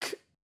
戦闘 ダメージ ボイス 声素材 – Damage Voice